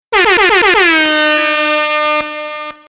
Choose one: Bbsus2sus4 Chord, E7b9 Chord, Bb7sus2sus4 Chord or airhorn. airhorn